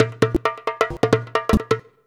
Index of /90_sSampleCDs/USB Soundscan vol.56 - Modern Percussion Loops [AKAI] 1CD/Partition C/17-DJEMBE133
133DJEMB06.wav